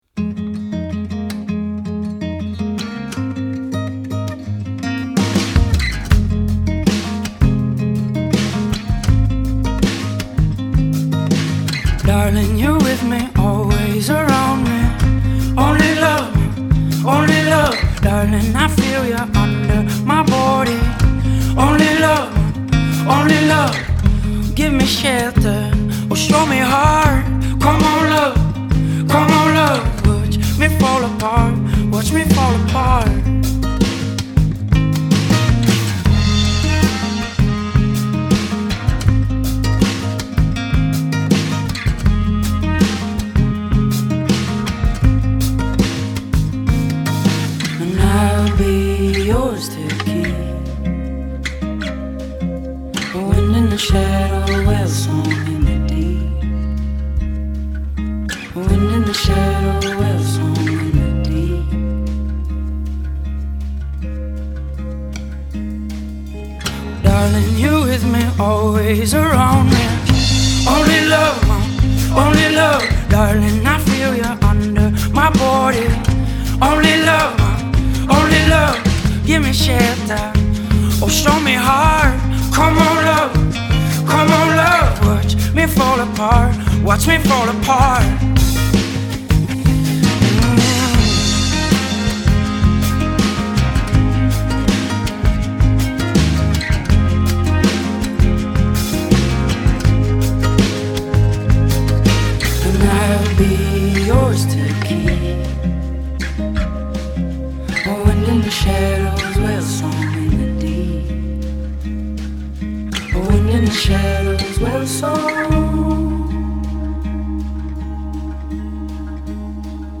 The record is very much an easy listening experience.